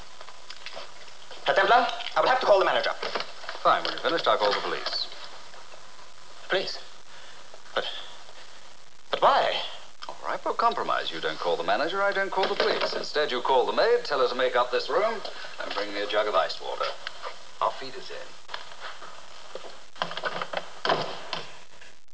Simon Templar Works his Magic with the Bellboy... (Episode: "The Helpful Pirate")